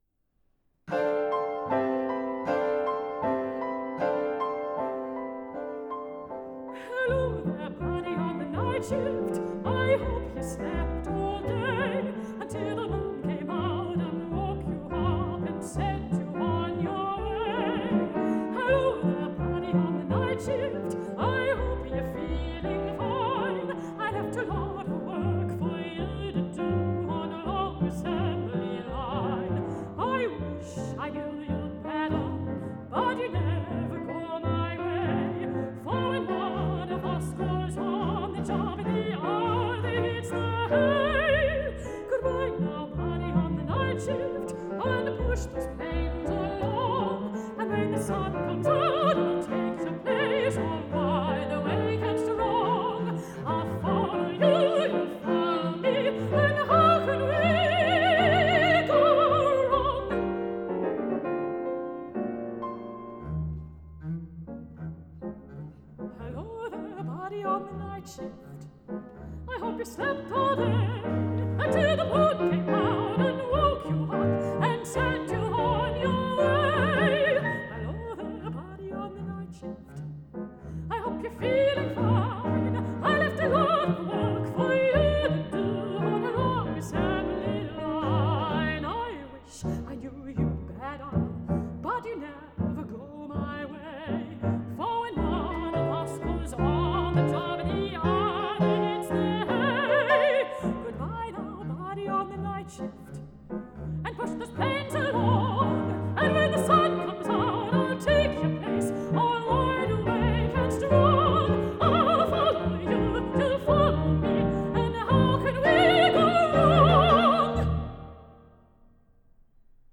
mezzo-soprano
accordion
double-bass
piano